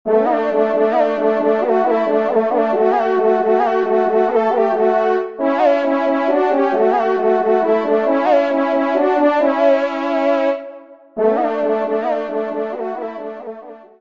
Genre : Musique Religieuse pour  Quatre Trompes ou Cors
Pupitre 3° Trompe